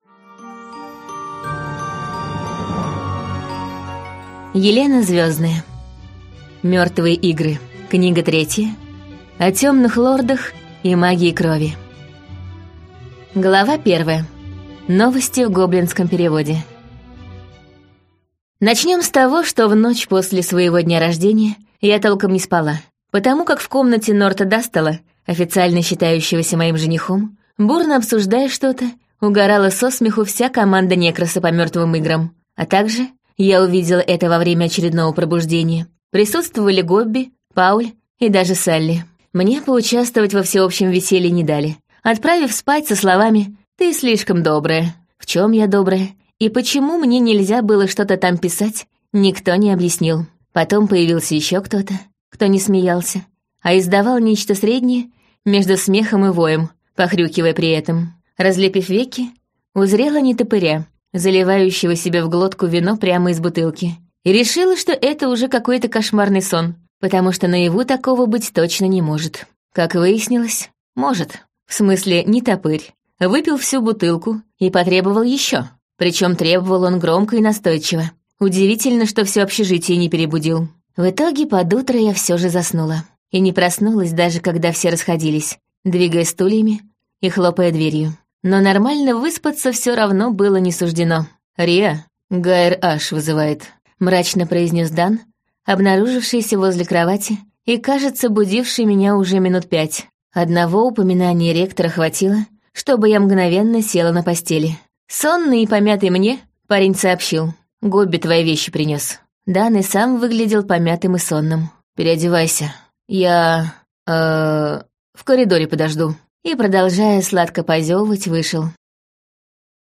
Аудиокнига Мертвые игры. Книга третья. О темных лордах и магии крови - купить, скачать и слушать онлайн | КнигоПоиск